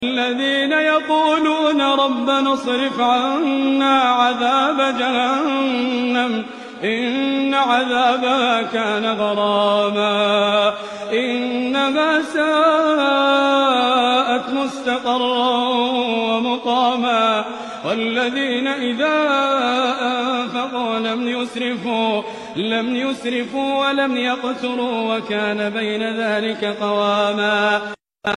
Quran Tilawat emotional 😴🎧|beautiful voice sound effects free download